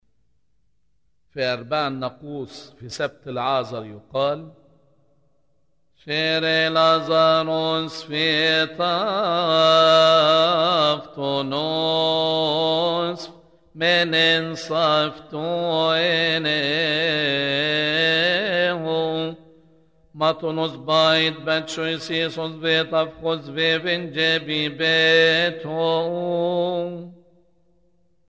لحن: أرباع الناقوس في سبت لعازر